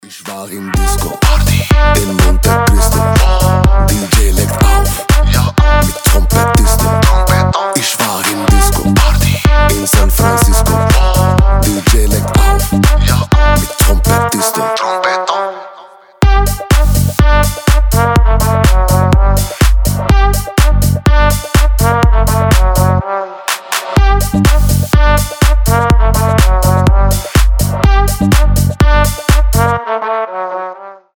• Качество: 320, Stereo
веселые
house
труба